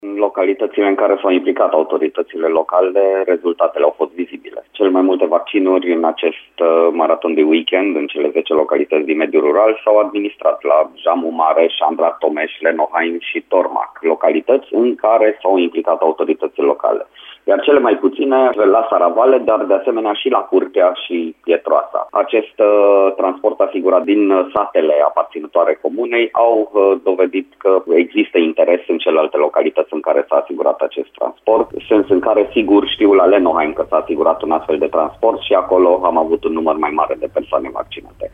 Prefectul a declarat, la Radio Timișoara, că în localitățile în care primarii s-au implicat și au pus la dispoziție transport pentru locuitorii din satele aparținătoare, numărul persoanelor care au ales să se imunizeze a fost mare.